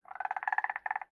1.21.5 / assets / minecraft / sounds / mob / frog / idle3.ogg